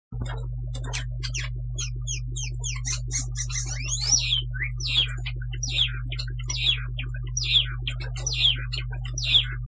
↓ ※音が小さいです ↓
ブレイの猿叫 　・・・無礼、野放図なブレイのさえずり
擬音化「チュチュチュチュ・チュイーン・チュ、ホッポコチーヨ・ホッポコチーヨ・ホッポポケチーヨ」